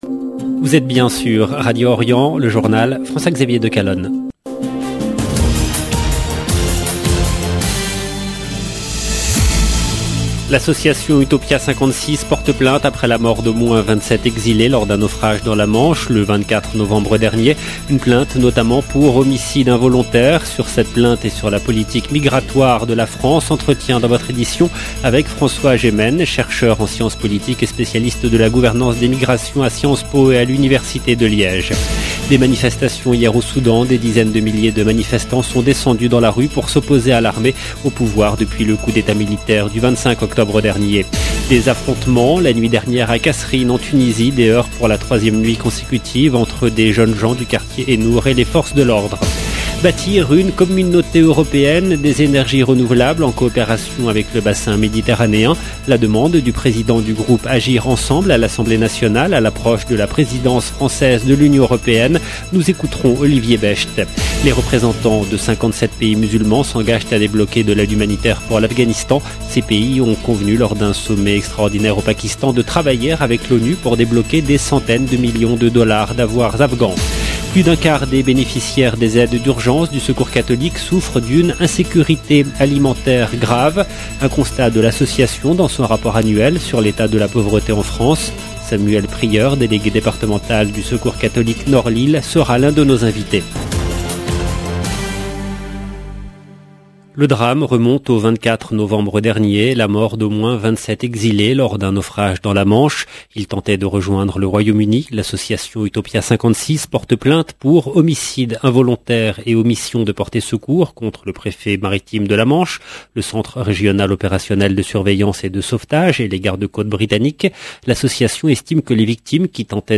LE JOURNAL DU SOIR EN LANGUE FRANCAISE DU 20/12/21